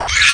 pain.wav